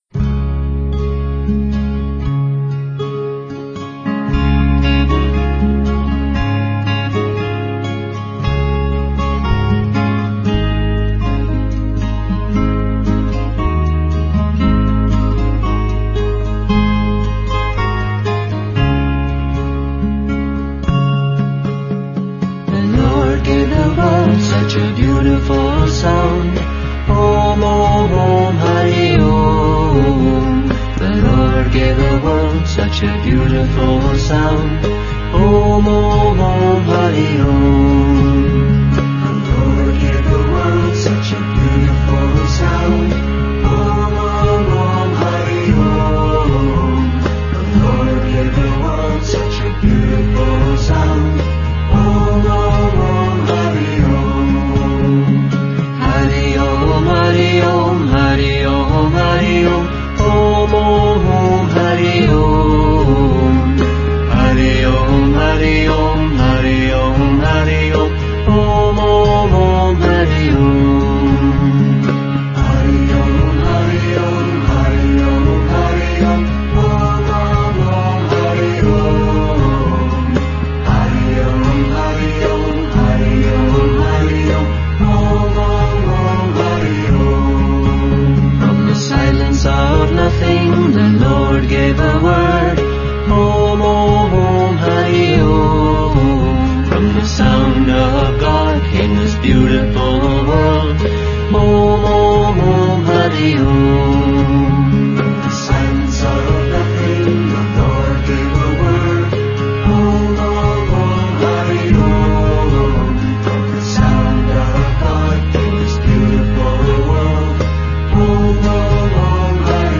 1. Devotional Songs
Major (Shankarabharanam / Bilawal)
8 Beat / Keherwa / Adi